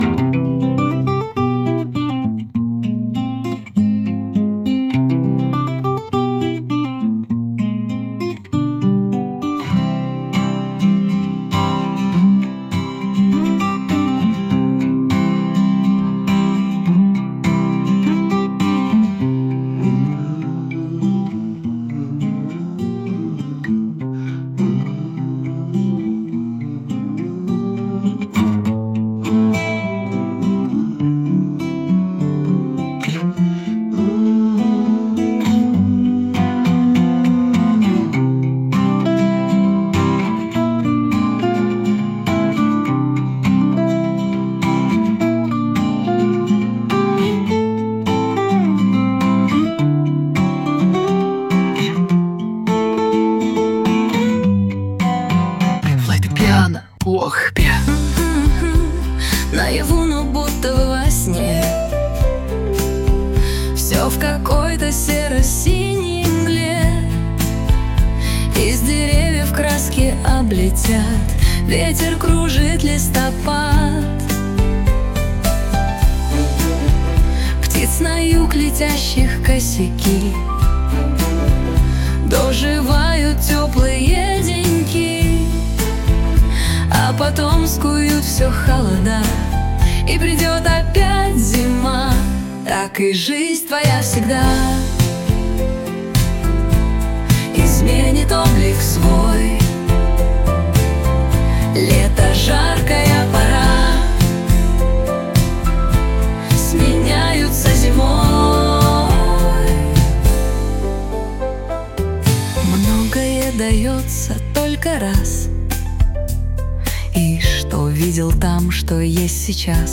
• 4: Рок